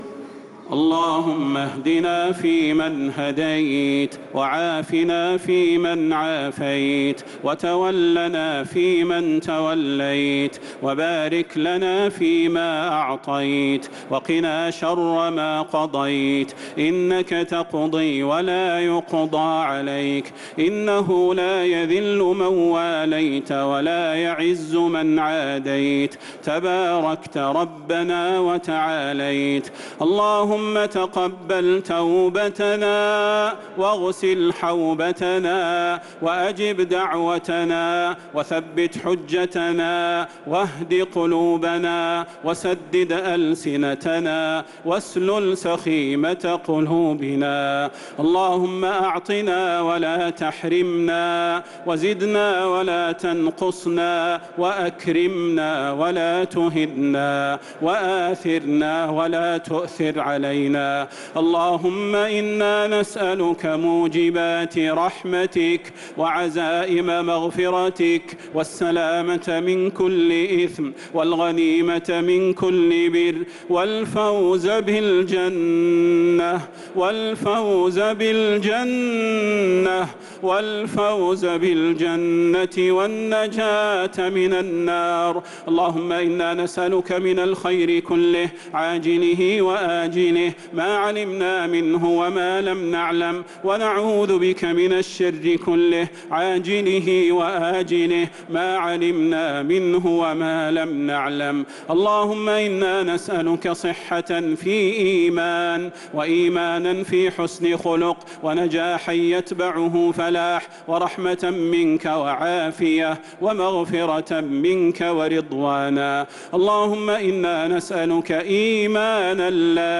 دعاء القنوت ليلة 13 رمضان 1446هـ | Dua 13th night Ramadan 1446H > تراويح الحرم النبوي عام 1446 🕌 > التراويح - تلاوات الحرمين